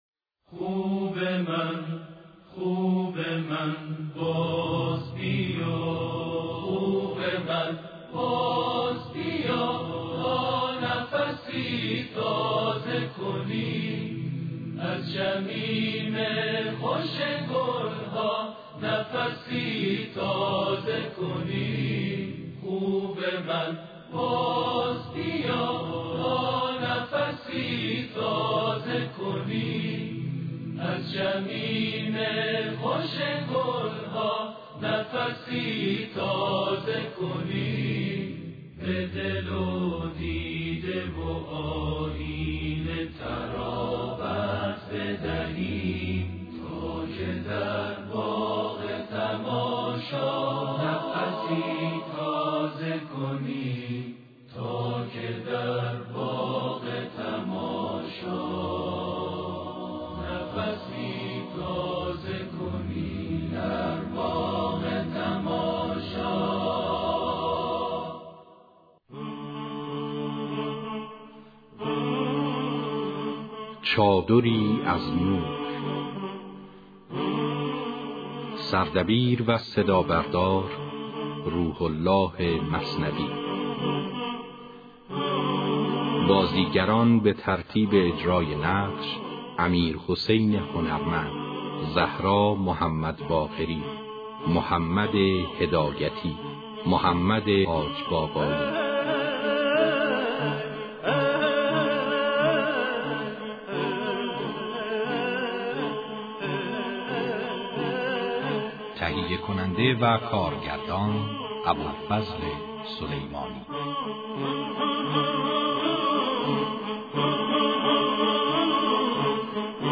نمایش رادیویی | چادری از نور